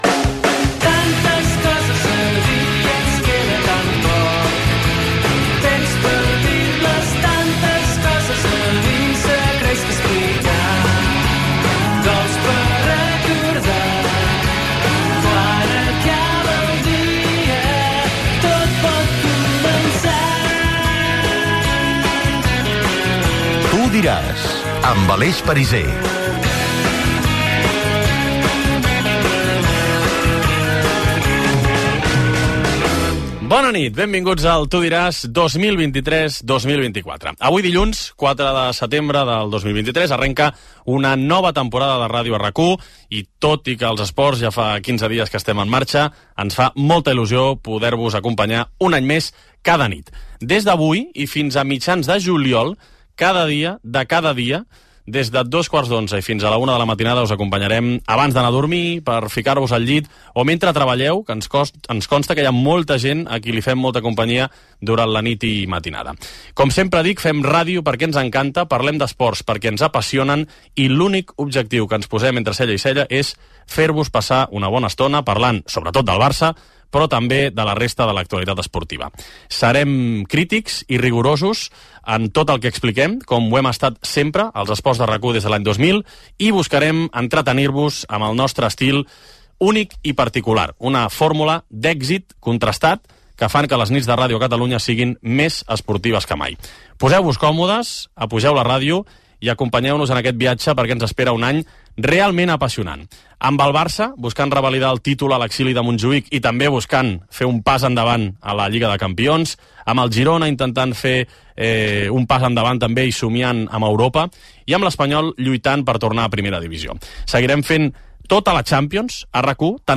Sintonia cantada del programa
Gènere radiofònic Esportiu